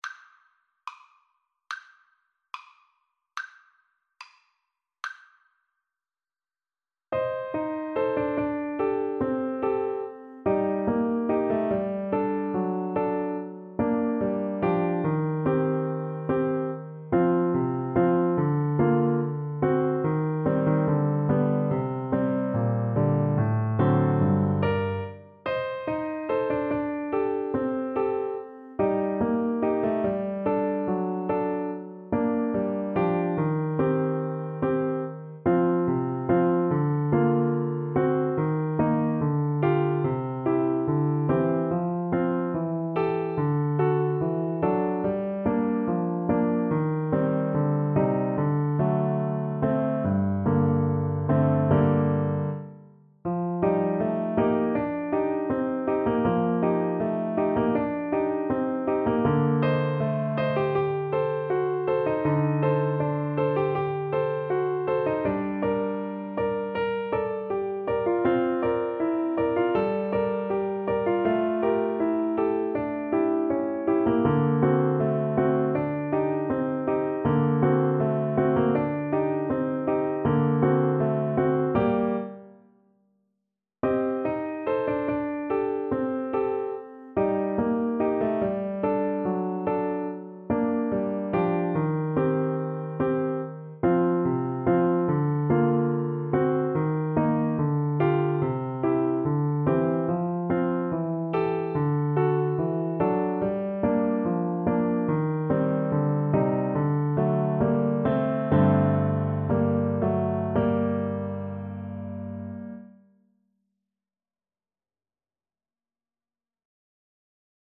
~ = 72 Andantino (View more music marked Andantino)
2/4 (View more 2/4 Music)
Classical (View more Classical Clarinet Music)